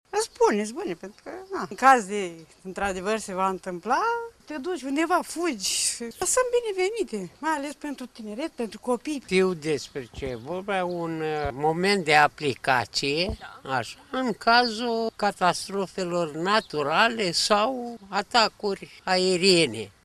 La ora 10.35, sirenele au simulat anunțarea unui dezastru.
vox-focsani.mp3